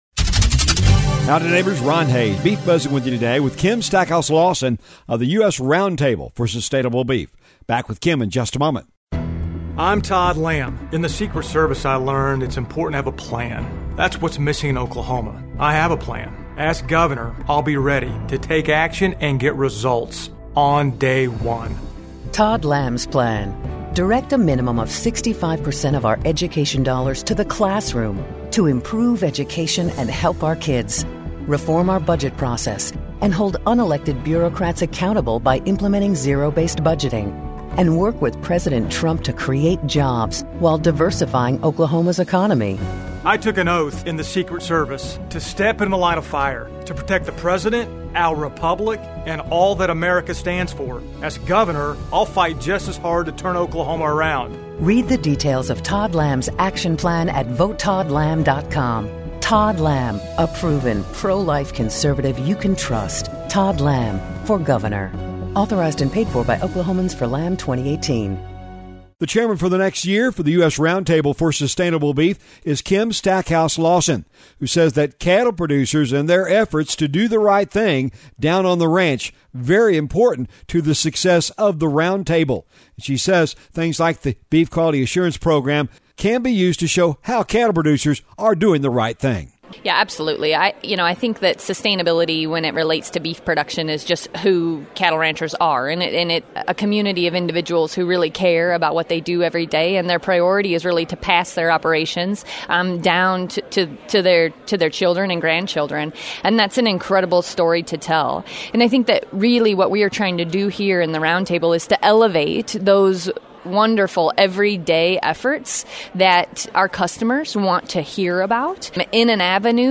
The Beef Buzz is a regular feature heard on radio stations around the region on the Radio Oklahoma Network and is a regular audio feature found on this website as well.